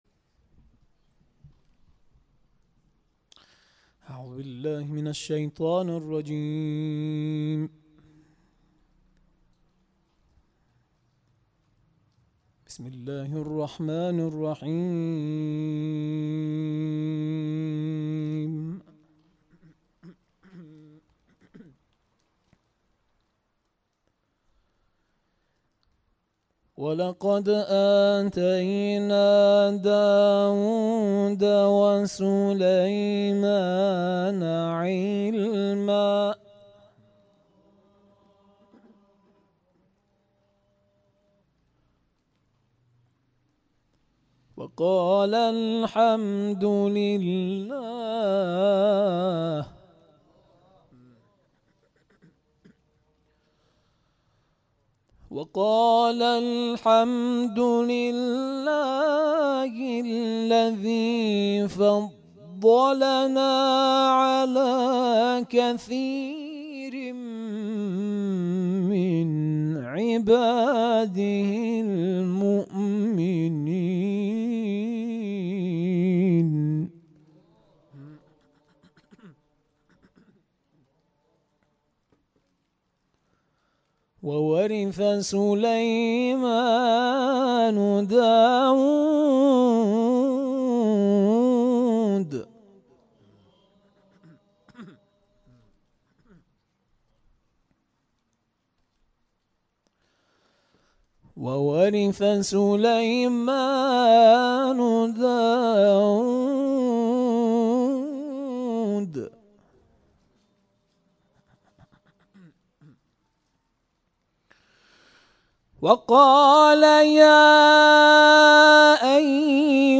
این تلاوت شب گذشته 17 اردیبهشت‌ماه در جلسه هفتگی پایگاه بسیج شهید چمران اجرا شده است.